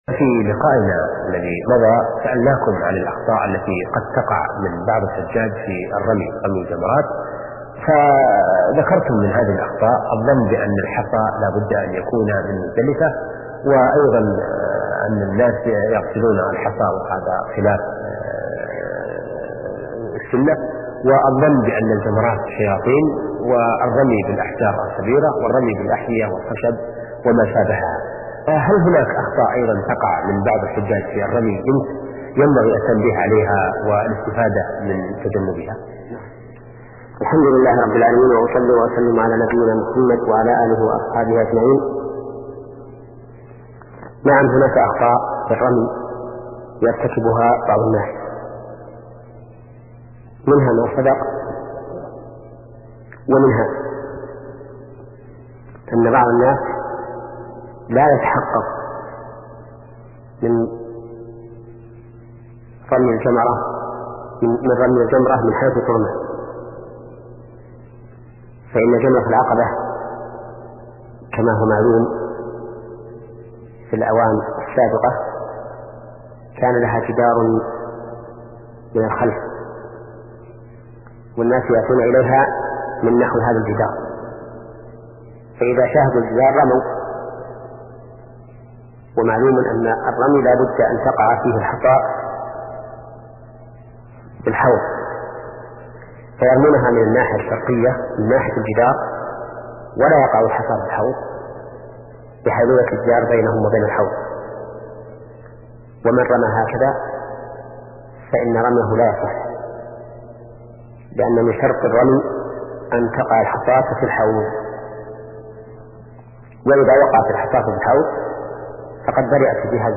شبكة المعرفة الإسلامية | الدروس | فقه العبادات (56) |محمد بن صالح العثيمين